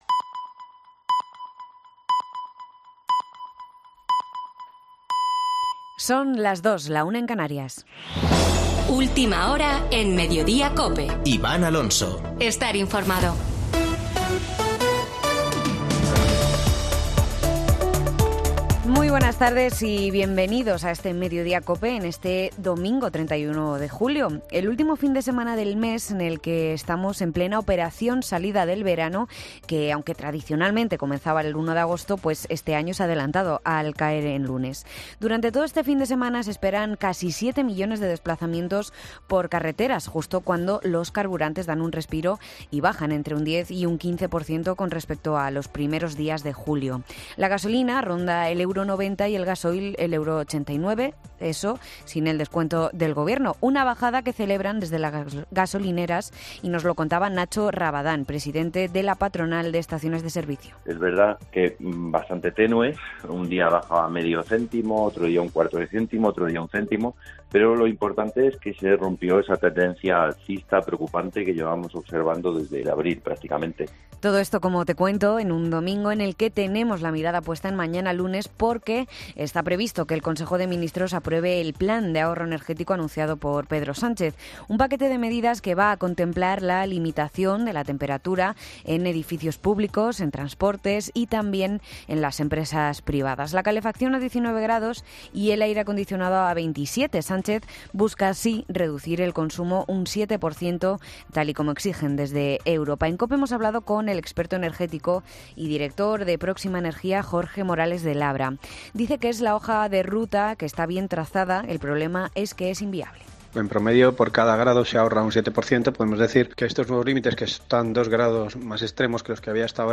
AUDIO: Boletín de noticias de COPE del 31 de julio de 2022 a las 14.00 horas